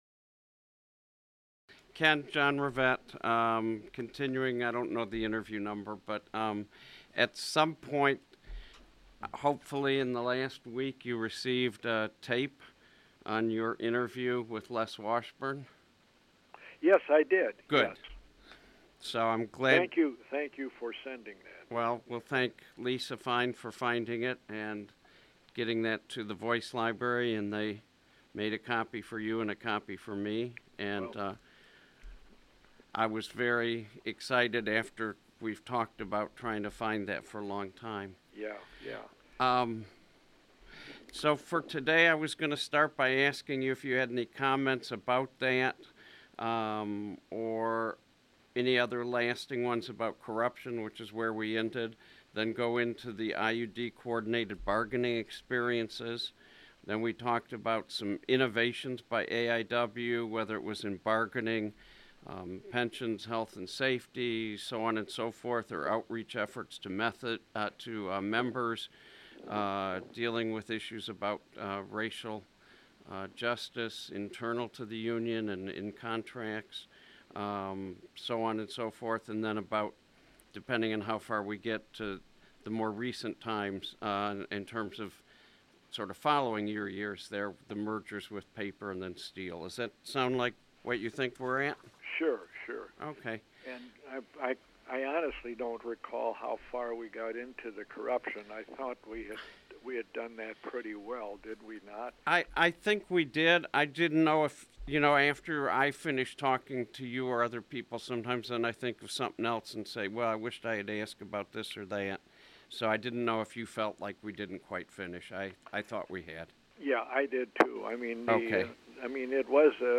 via telephone